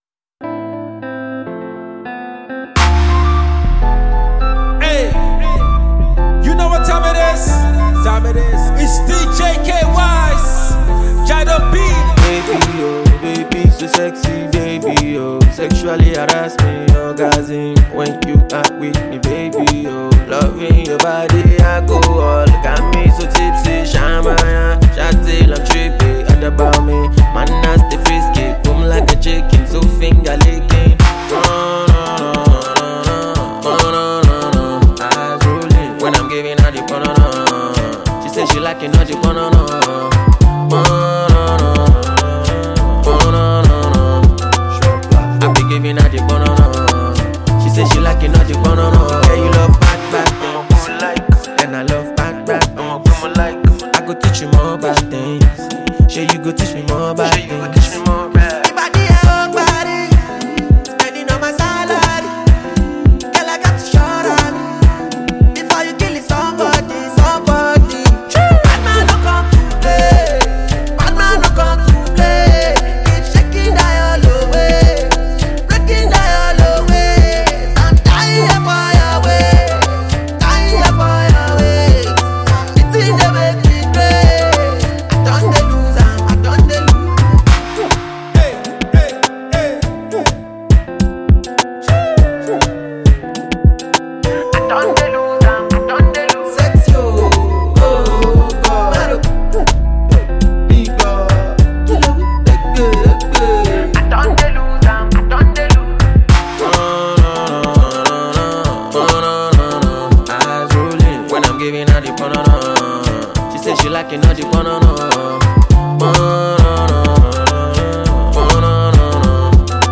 Nigerian disc jockey
talented rapper
mid-tempo song